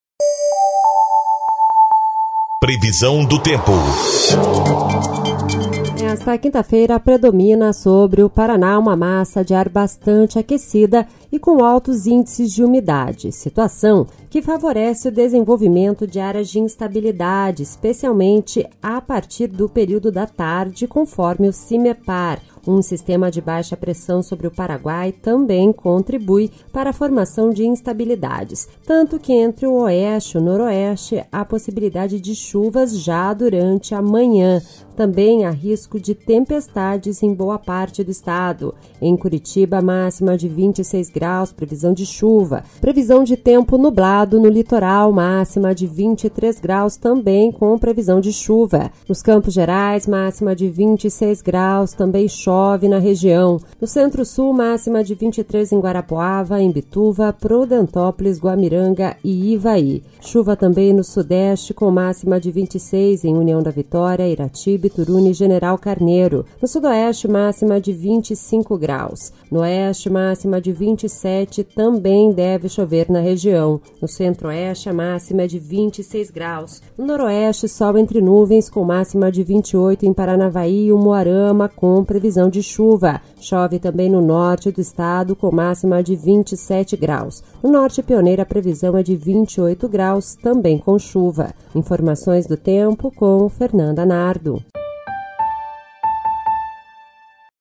Previsão do Tempo (23/02)